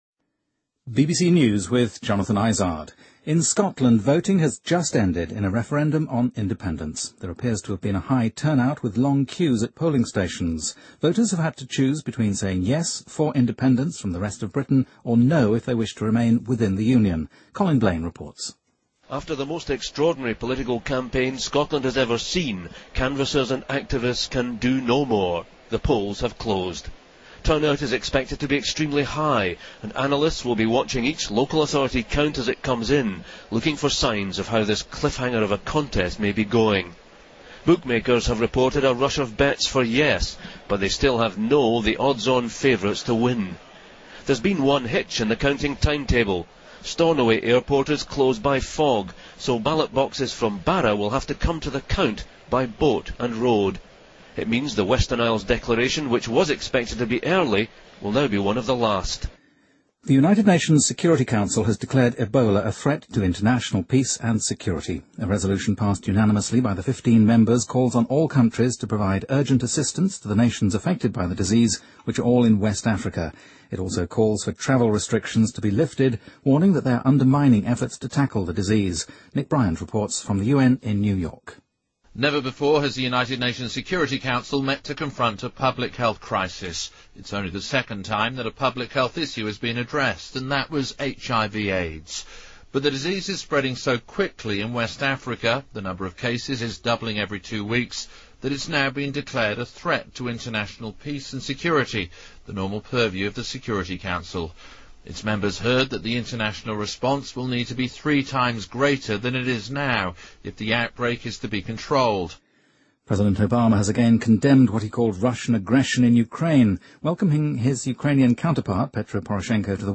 BBC news,苏格兰独立公投投票结束